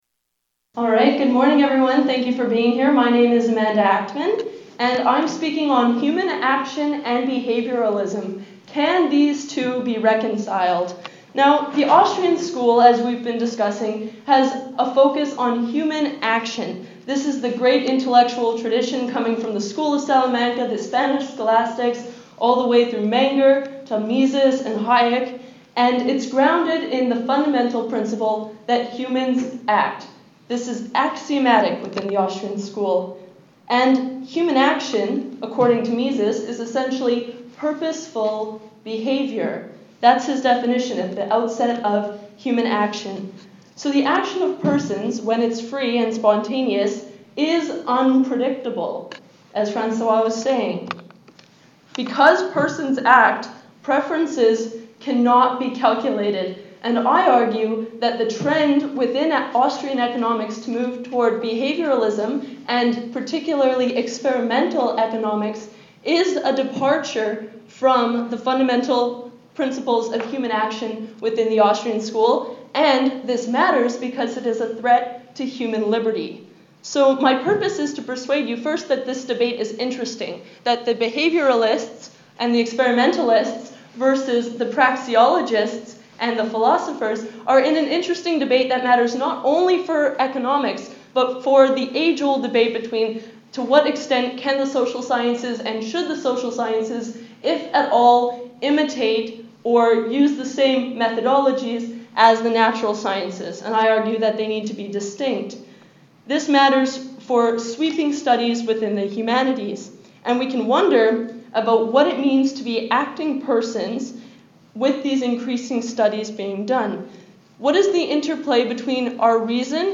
I presented this paper at the American Political Science Association annual conference in August 2014.